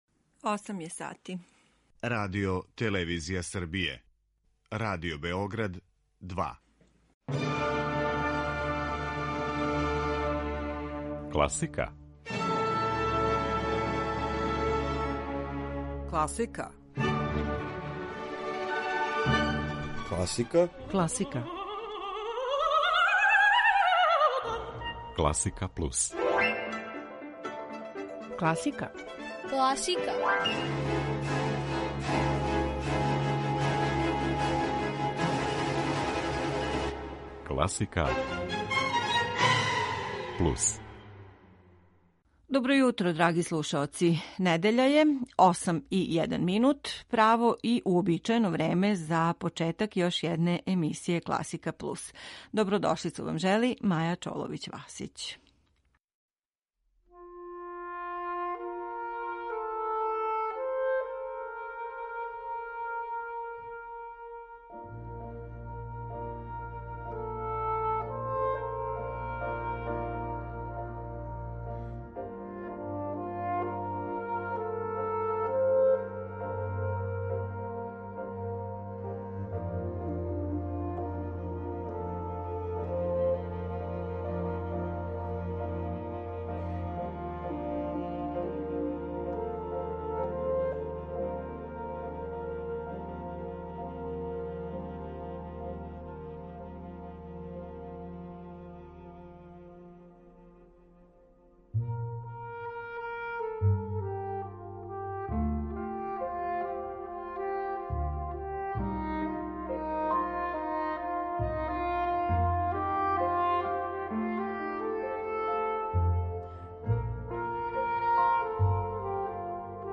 Емисија класичне музике
пијаниста
виолончелиста
саксофонисткиња